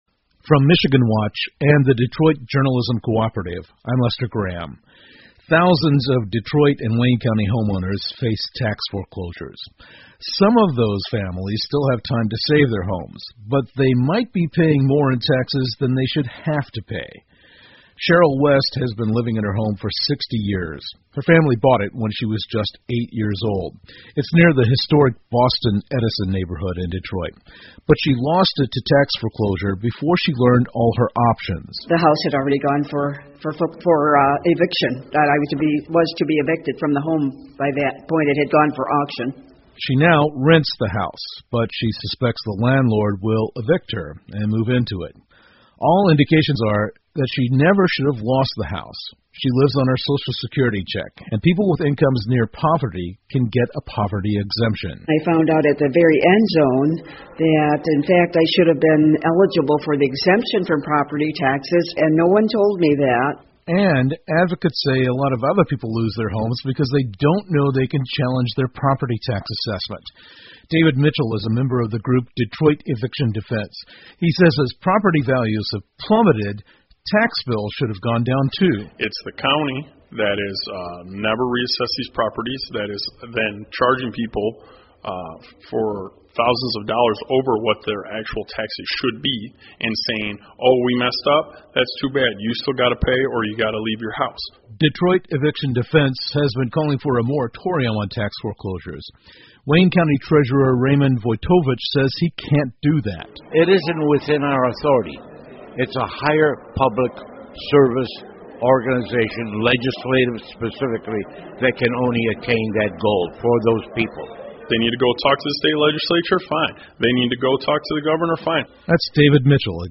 密歇根新闻广播 韦恩县的人可以不用因为纳税抵押而失去房子 听力文件下载—在线英语听力室